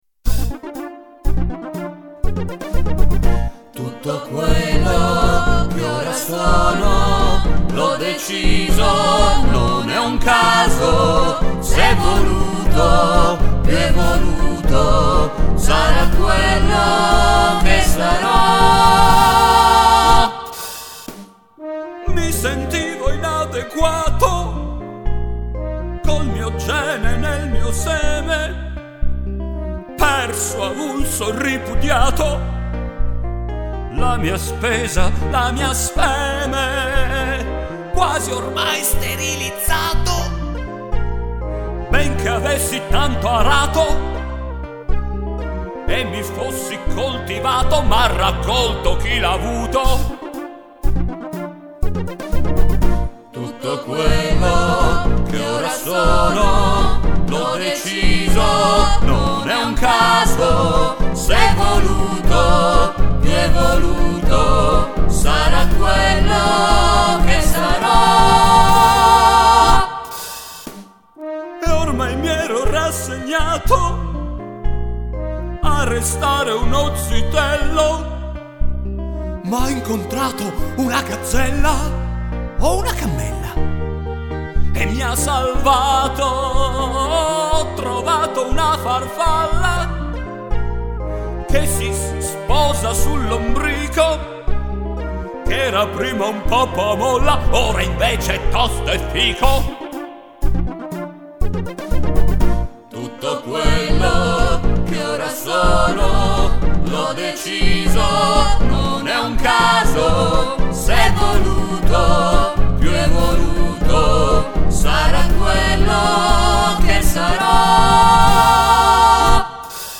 Il brano originale, leitmotiv dello spettacolo:
strumentario Orff (xilofoni vari, piccole percussioni)